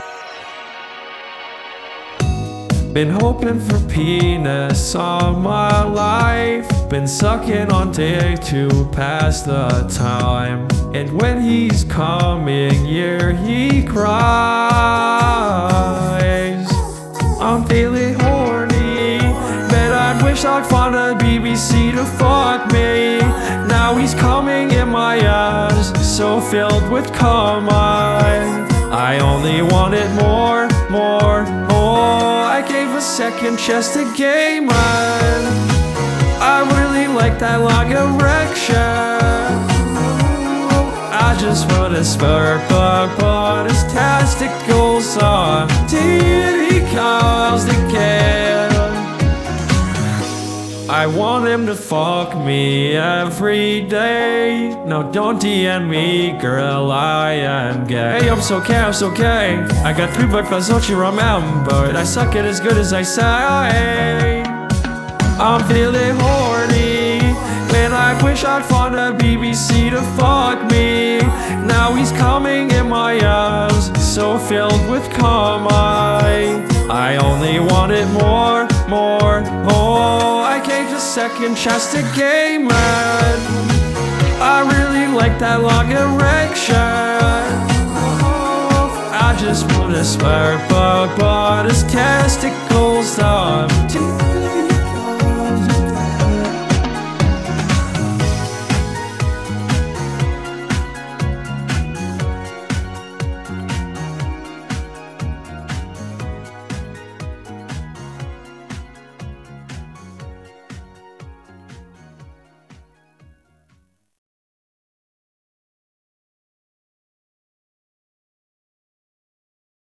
singing.wav